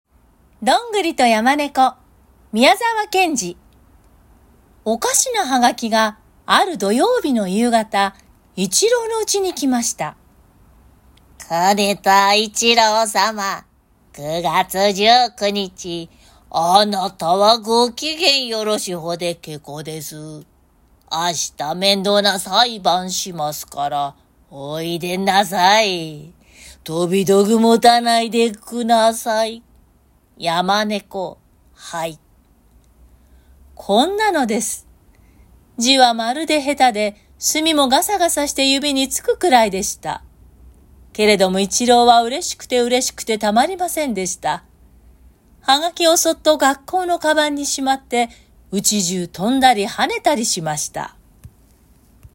朗読音源サンプル　🔽